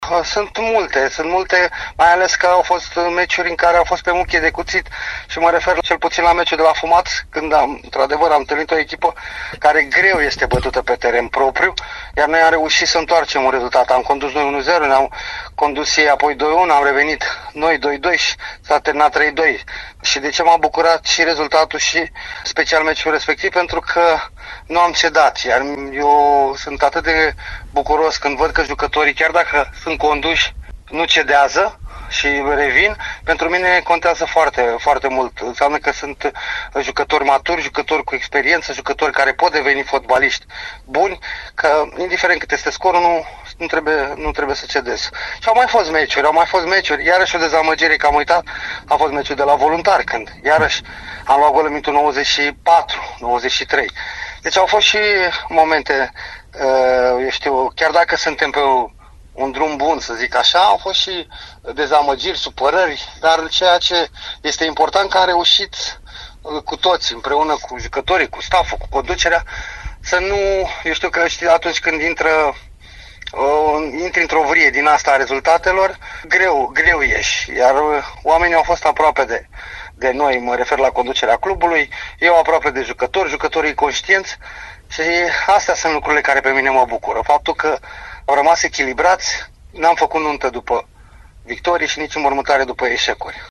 Antrenorul Flavius Stoican a analizat, la Radio Timișoara, parcursul din actuala stagiune a echipei sale.